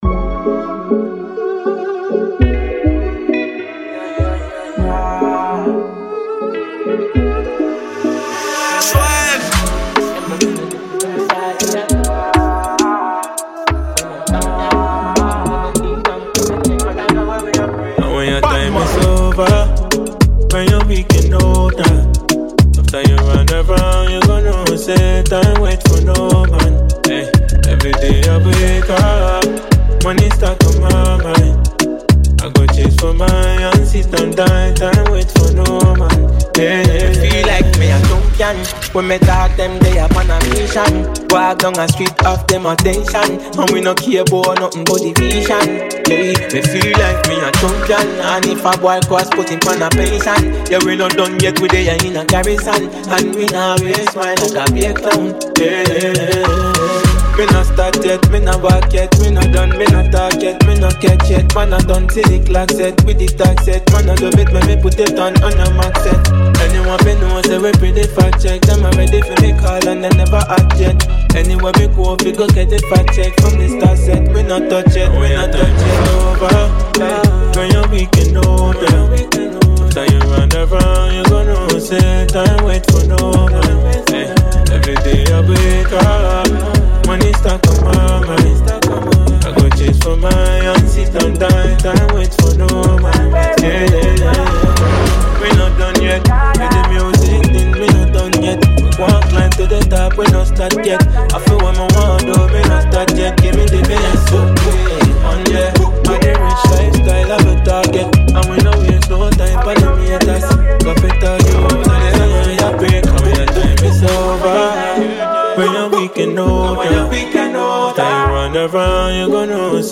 reggae dancehall